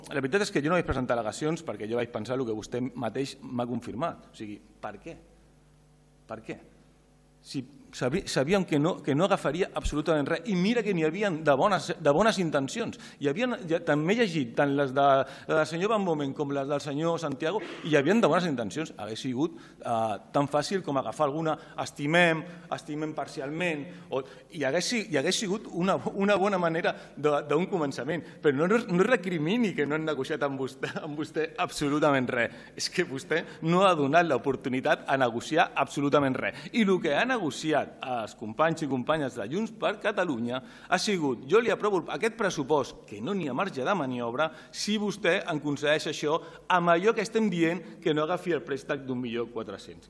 El regidor no adscrit, Albert Sales, ha respost dient que no havia donat l’opció de negociar res, només els 1,4 milions d’inversió amb la qual no estaven d’acord: